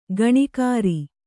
♪ gaṇikāri